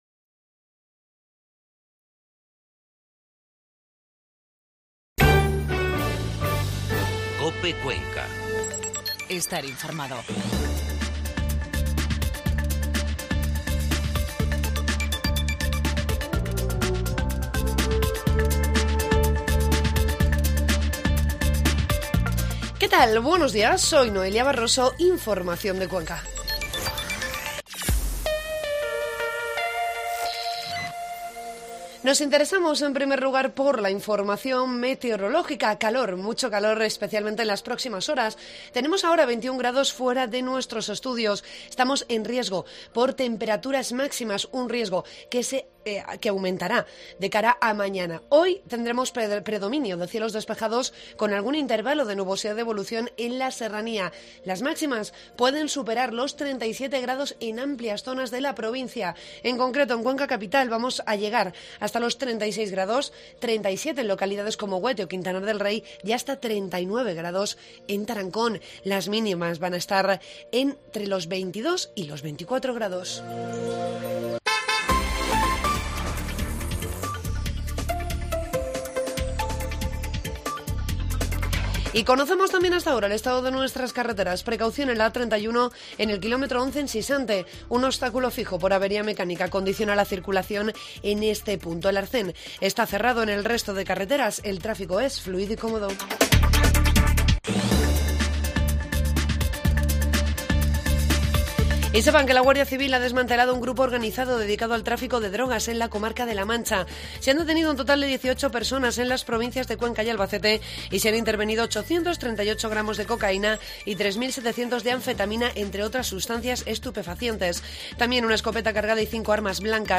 AUDIO: Informativo matinal 1 de agosto.